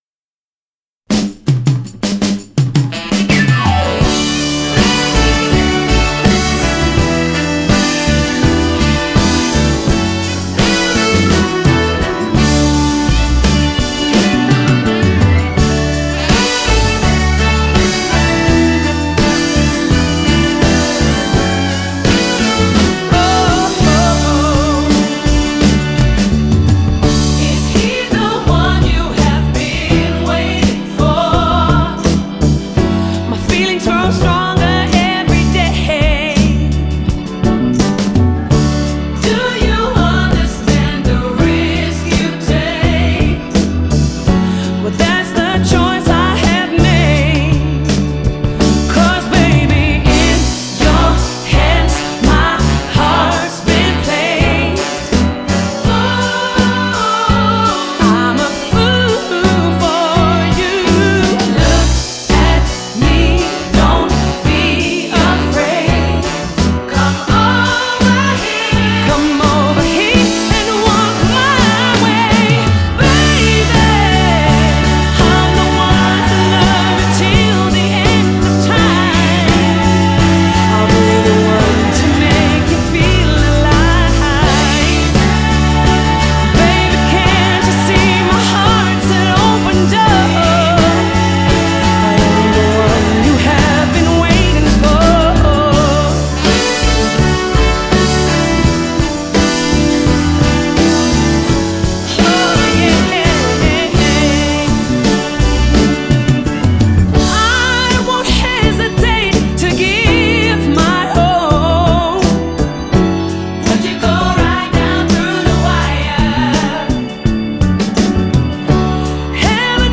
Genre: Pop / Soul / Jazz